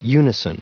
Prononciation du mot unison en anglais (fichier audio)
Prononciation du mot : unison